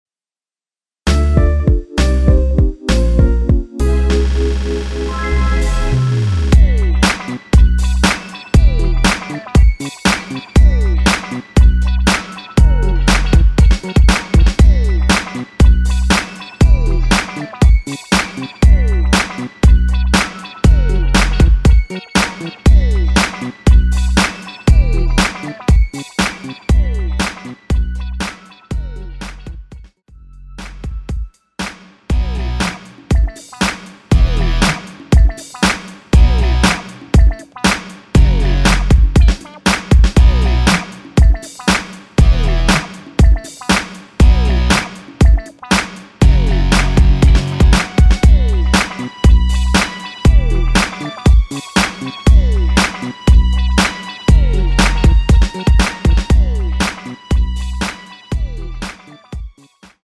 Bm
앞부분30초, 뒷부분30초씩 편집해서 올려 드리고 있습니다.
중간에 음이 끈어지고 다시 나오는 이유는